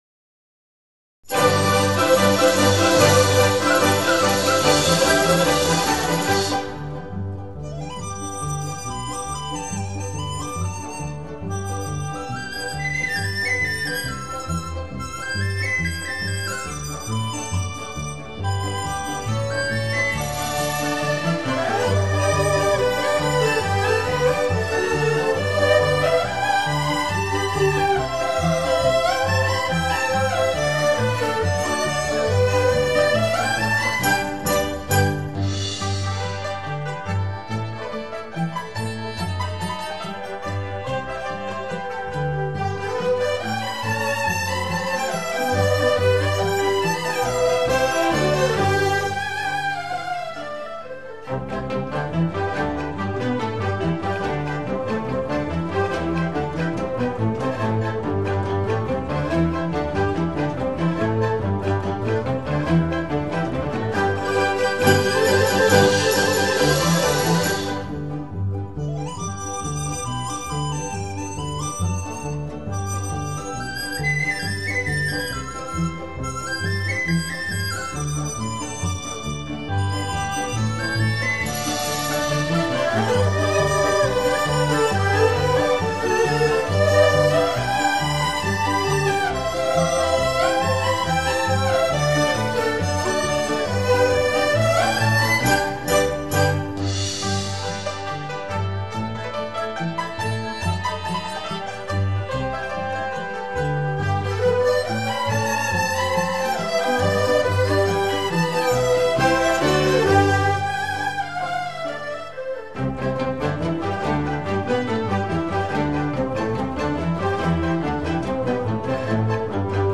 民乐大合奏
经典古乐合奏